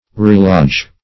Relodge \Re*lodge"\ (r[=e]*l[o^]j")